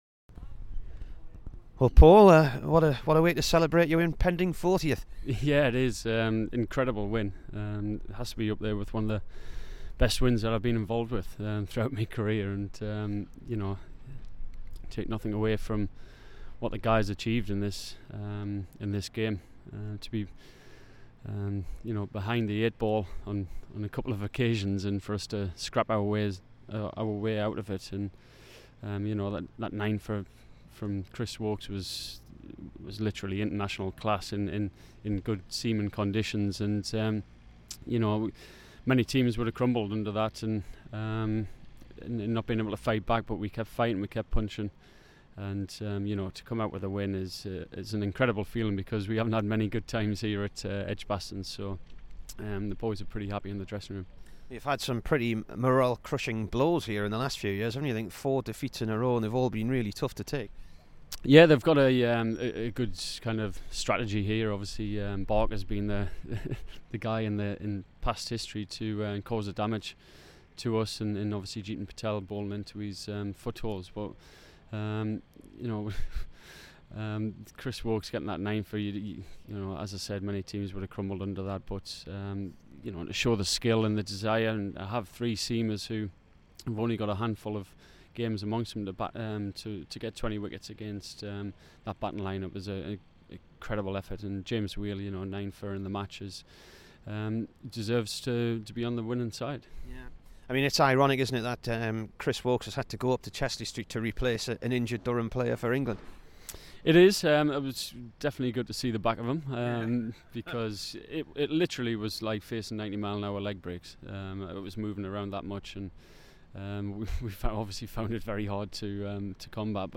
Here is the Durham skipper after the championship win at Edgbaston.